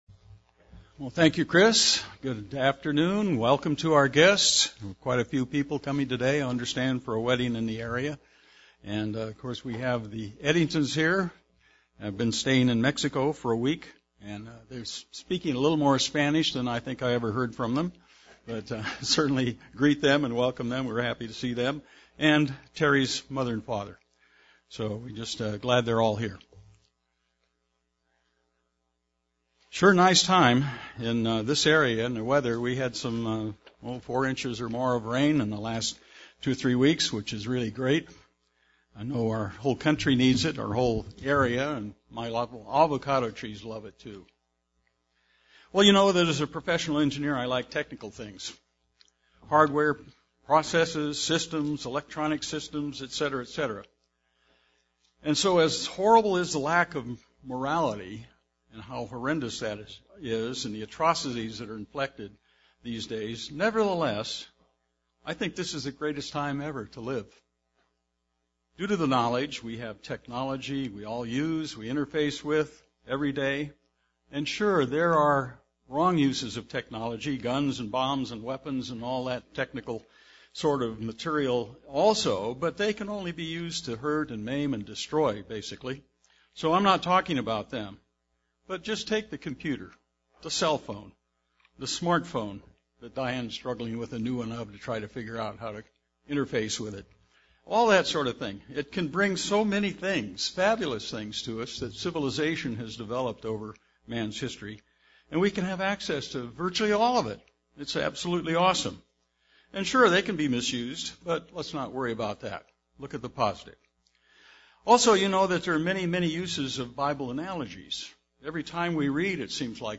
Given in San Diego, CA
UCG Sermon Studying the bible?